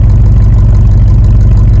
SUV_Idle.wav